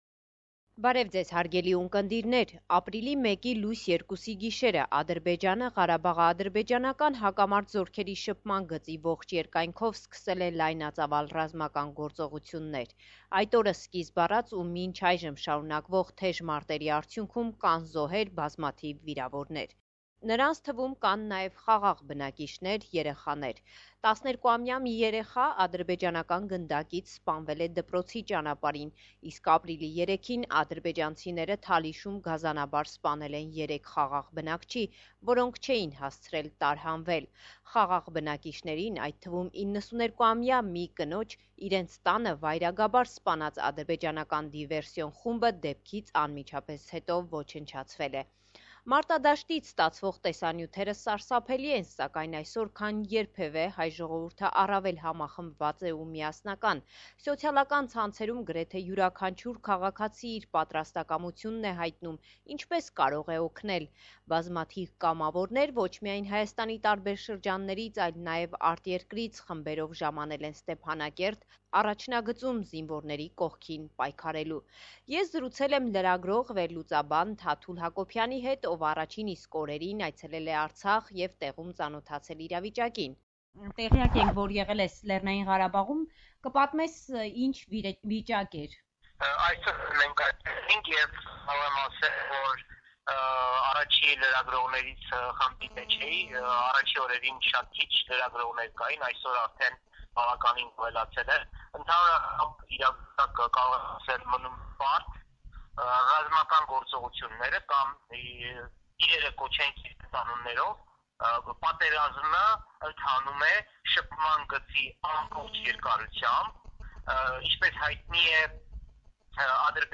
Latest News